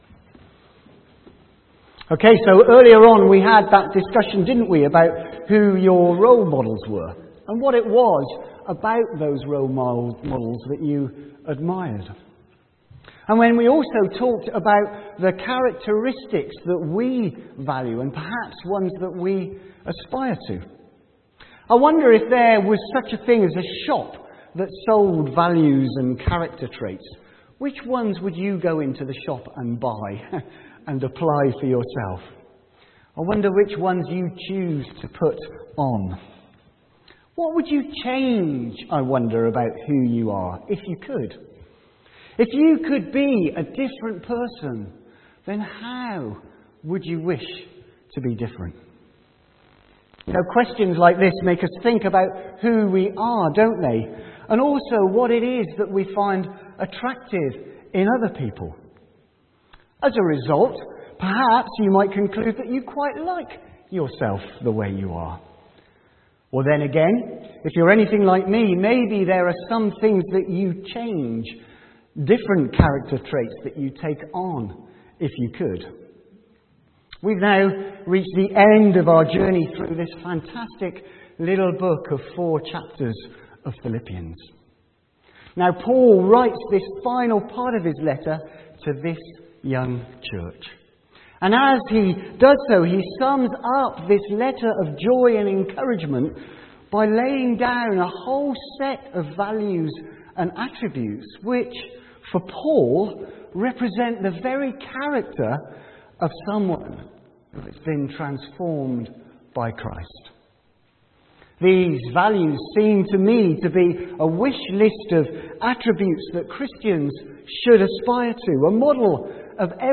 From Service: "9.00am Service"